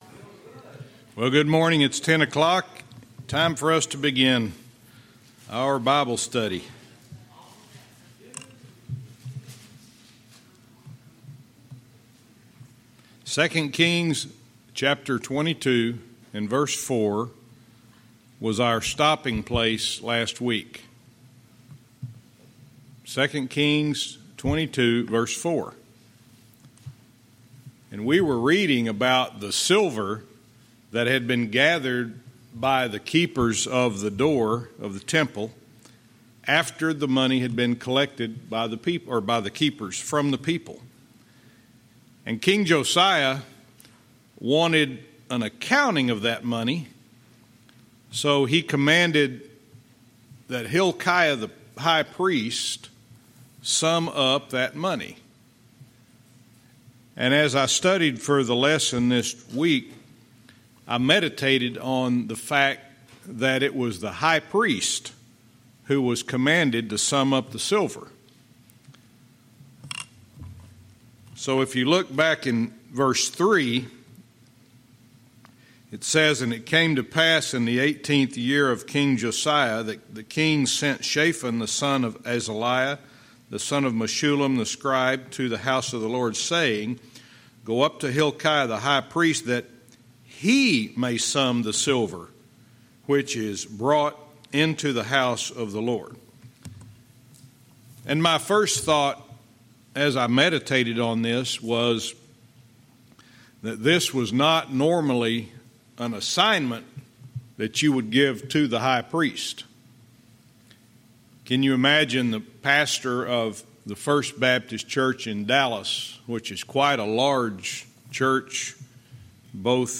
Verse by verse teaching - 2 Kings 22:4-6